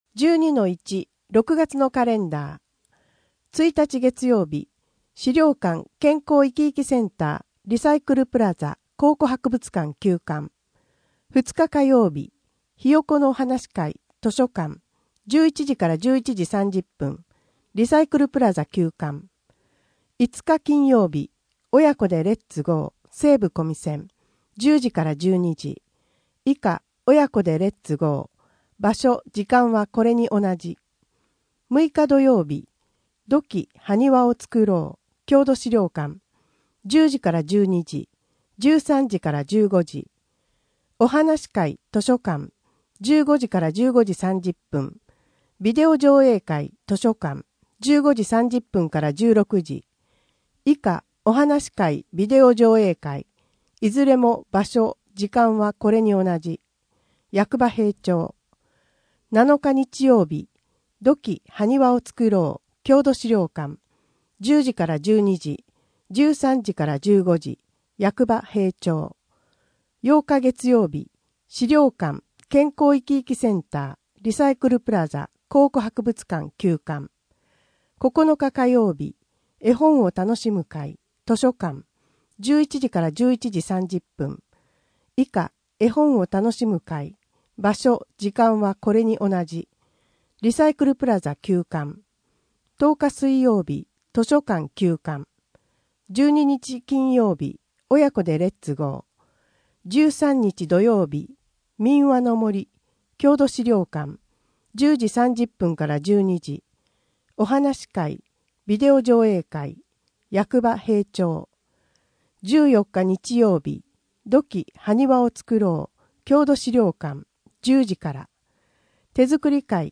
声の「広報はりま」6月号
声の「広報はりま」はボランティアグループ「のぎく」のご協力により作成されています。